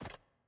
sfx_ui_map_panel_disabled.ogg